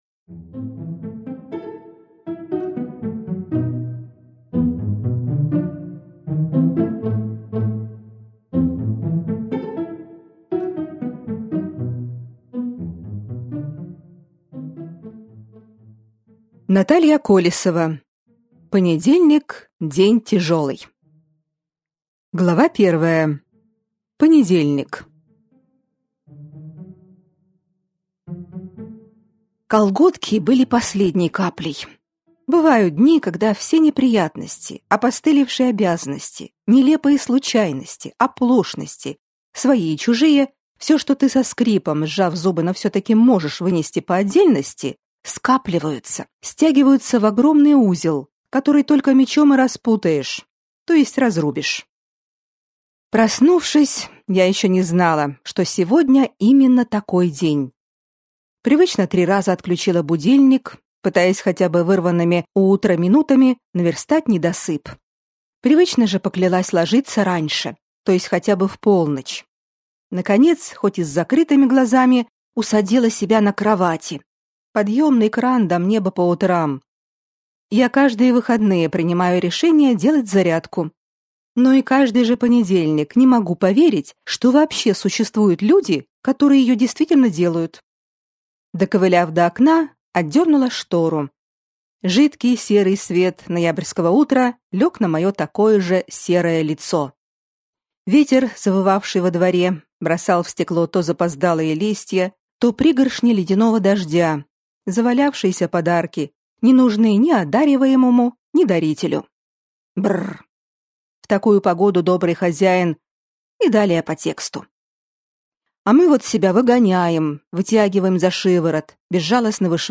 Аудиокнига Понедельник – день тяжёлый | Библиотека аудиокниг